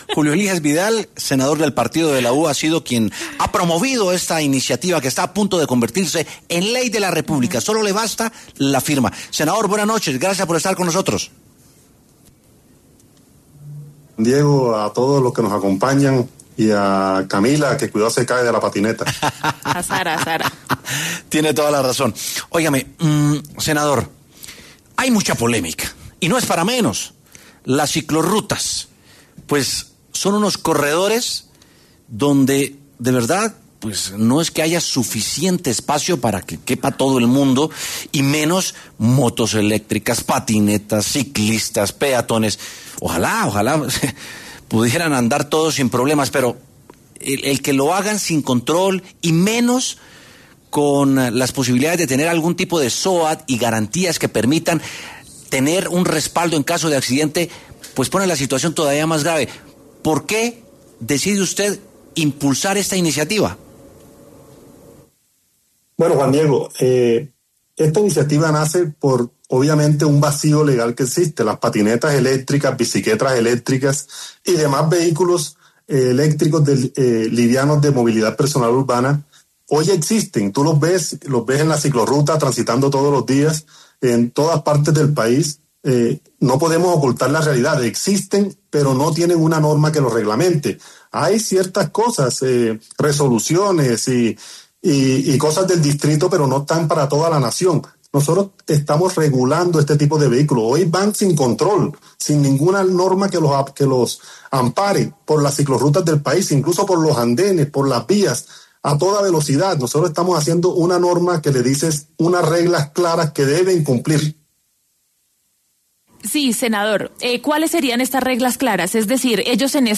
Debate: ¿Es viable que vehículos eléctricos transiten por la ciclovía? Proyecto, a punto de ser ley
W Sin Carreta habló con el senador que promovió el proyecto y con un experto en movilidad, quienes tienen puntos de vista diferentes sobre la circulación y normativa de los vehículos eléctricos.